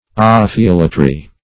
What does ophiolatry mean?
\O`phi*ol"a*try\ ([=o]`f[i^]*[o^]l"[.a]*tr[y^])